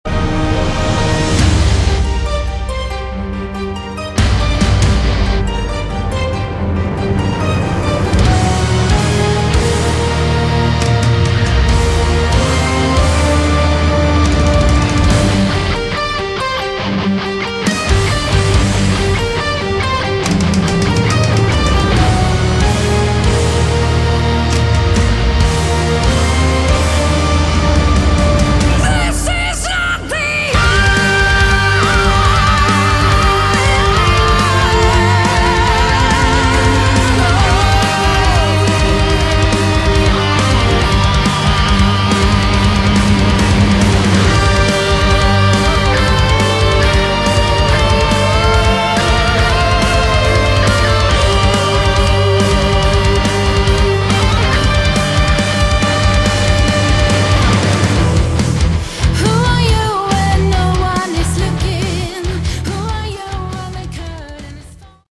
Category: Symphonic Concept Album